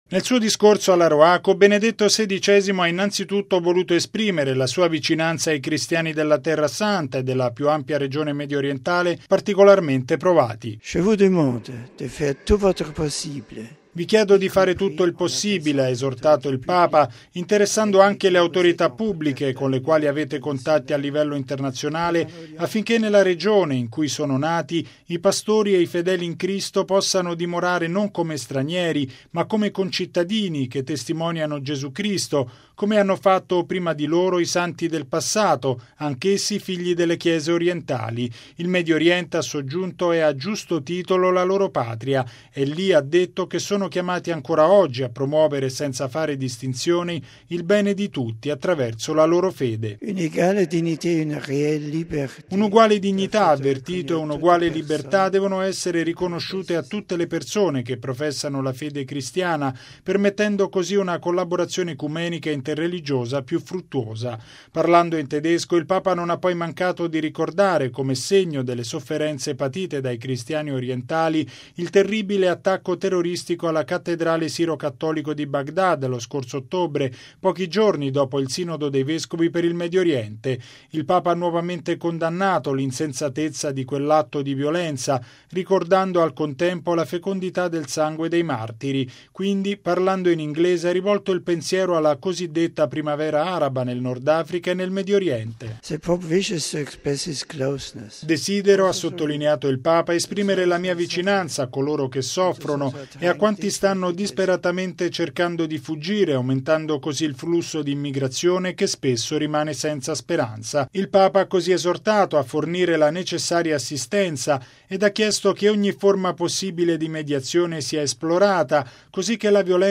Nel suo intervento in più lingue, il Pontefice ha auspicato pace ed armonia per i popoli del Medio Oriente, ribadendo che i cristiani di quella regione hanno il diritto di vivere come “concittadini” e non come “stranieri”.
Il servizio